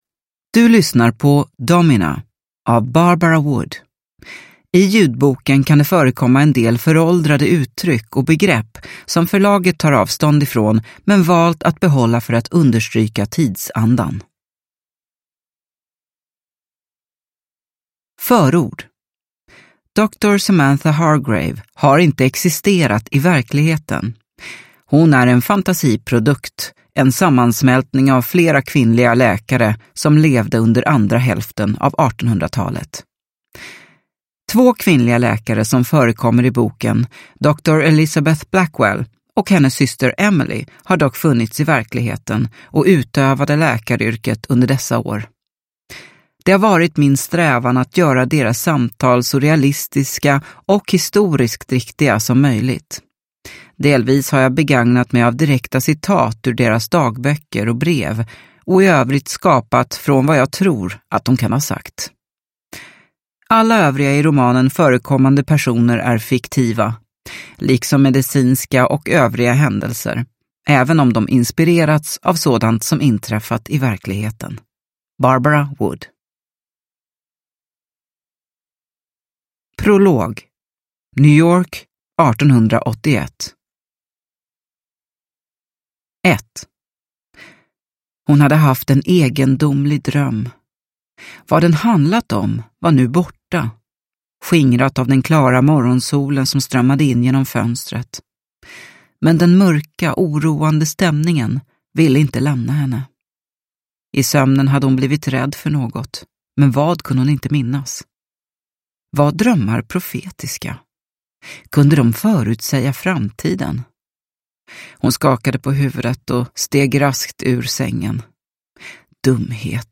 Domina – Ljudbok – Laddas ner